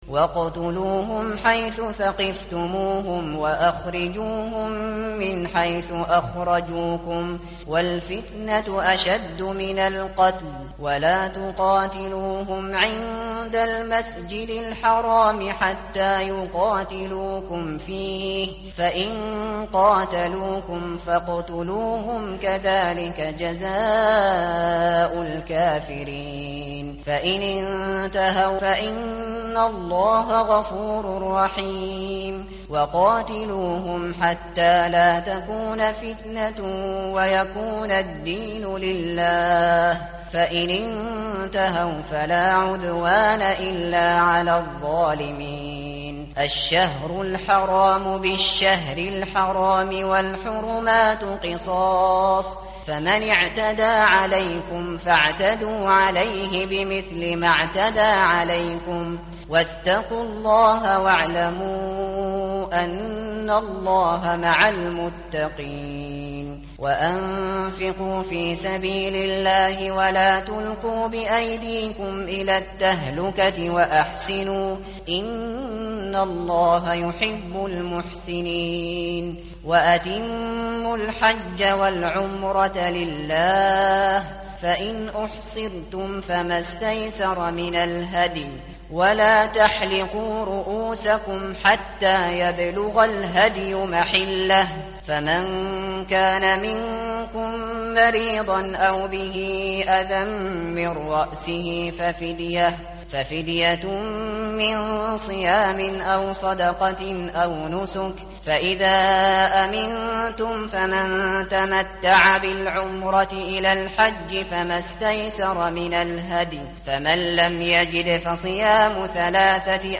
متن قرآن همراه باتلاوت قرآن و ترجمه
tartil_shateri_page_030.mp3